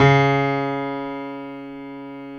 55p-pno13-C#2.wav